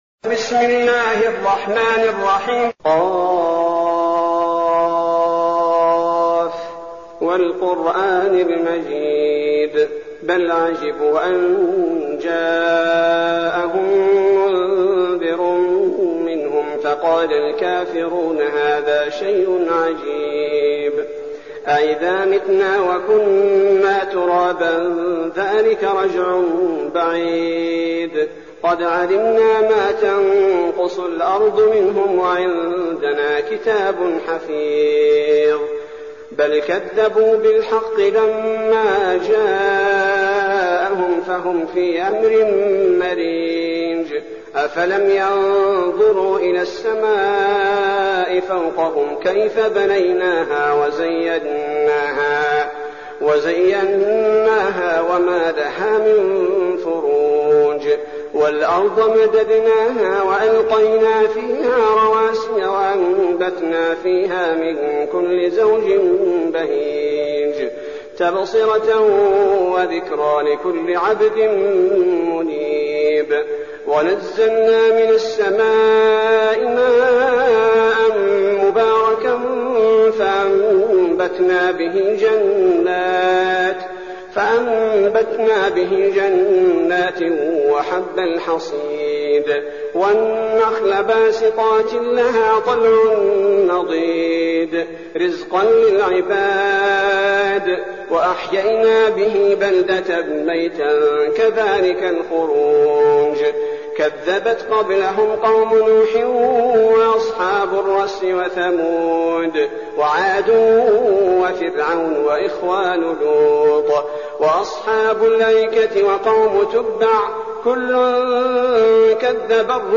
المكان: المسجد النبوي الشيخ: فضيلة الشيخ عبدالباري الثبيتي فضيلة الشيخ عبدالباري الثبيتي ق